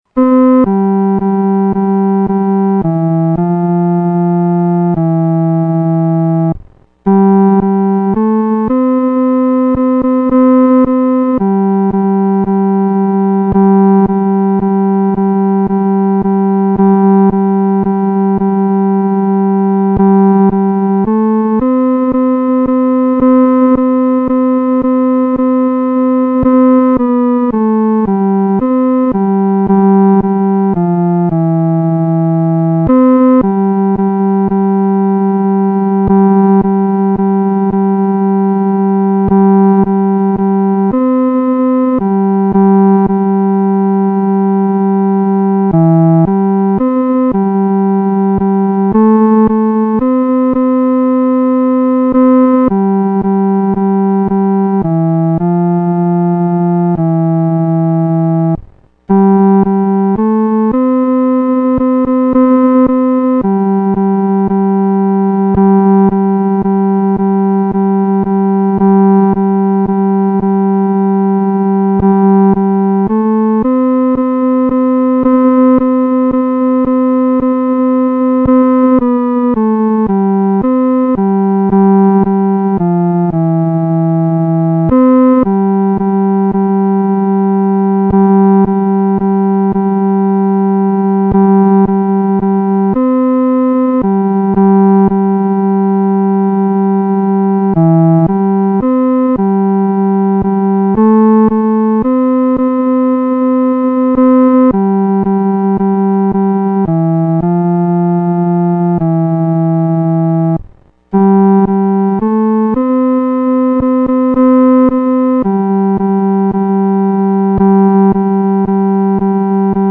独奏（第三声）
主翅膀下-独奏（第三声）.mp3